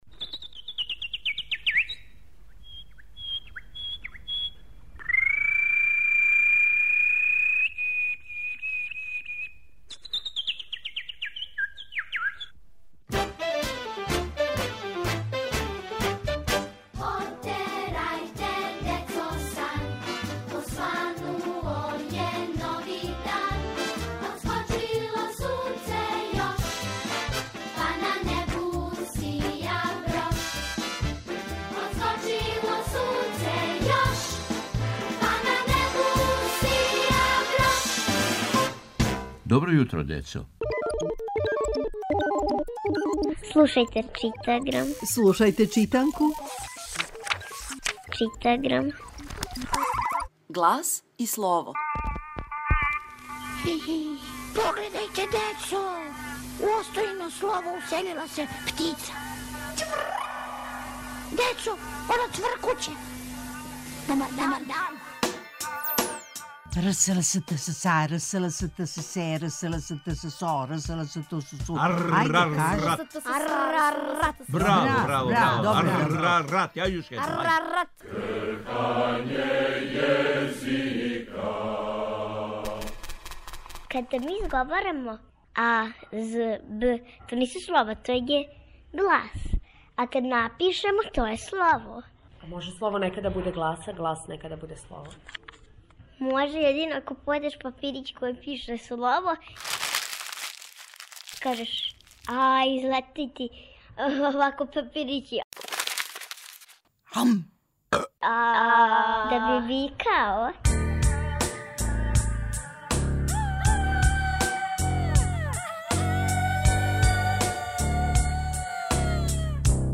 Читаграм је читанка која се слуша. Слушамо лекцију из читанке за 1. разред.